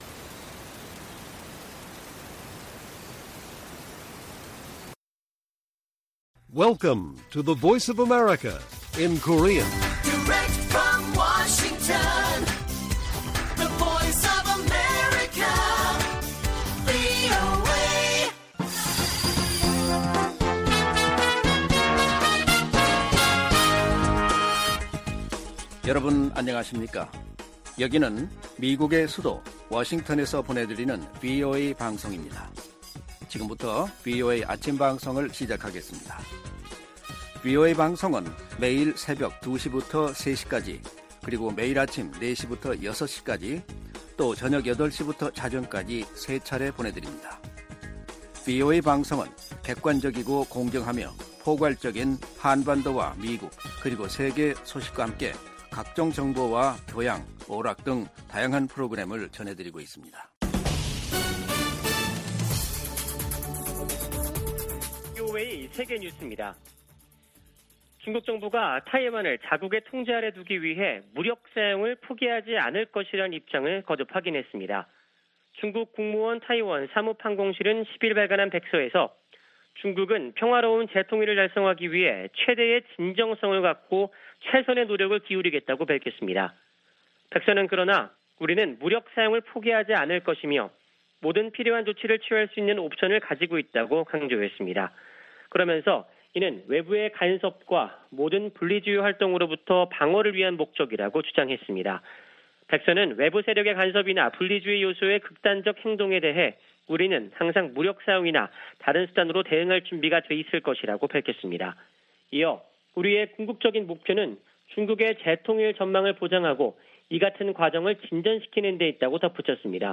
세계 뉴스와 함께 미국의 모든 것을 소개하는 '생방송 여기는 워싱턴입니다', 2022년 8월 11일 아침 방송입니다. 지구촌 오늘'에서는 크름반도 군사 시설에서 연쇄 폭발이 발생한 소식, '아메리카 나우'에서는 공화당이 도널드 트럼프 전 대통령 자택 압수 수색을 비판한 이야기 전해드립니다.